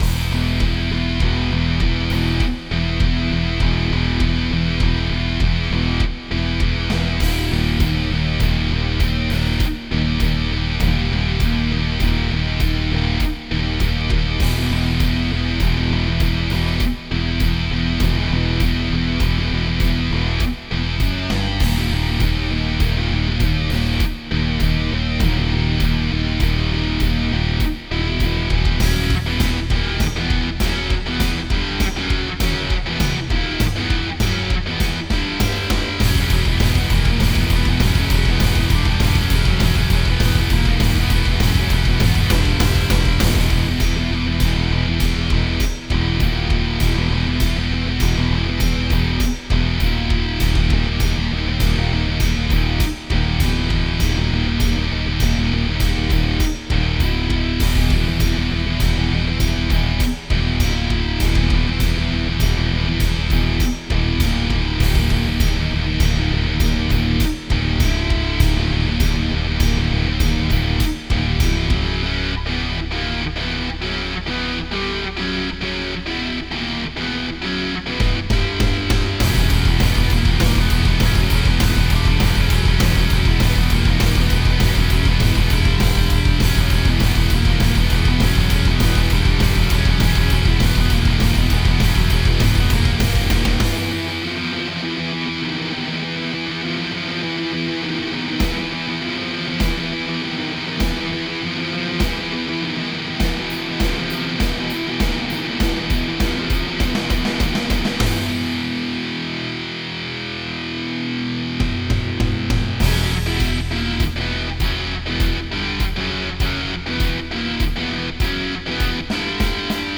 Untitled Metal Track
untitled_metal_track_1.ogg